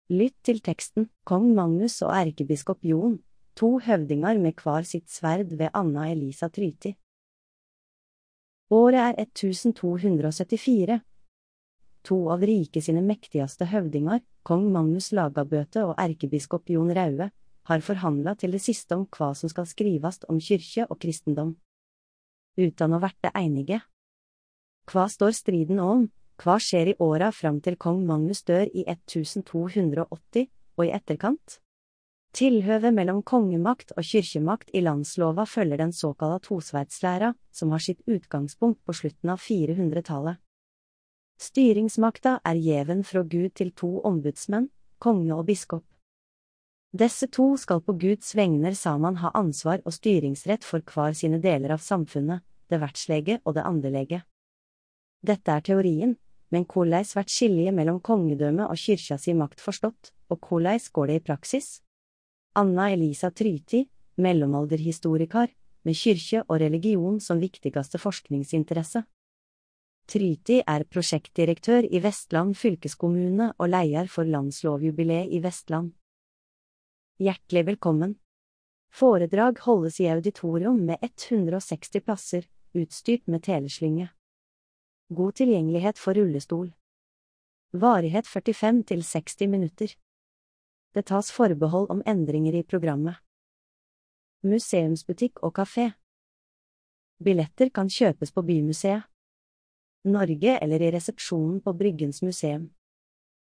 Kva står striden om, kva skjer i åra fram til kong Magnus dør i 1280 – og i etterkant? Populærvitenskapelige foredrag.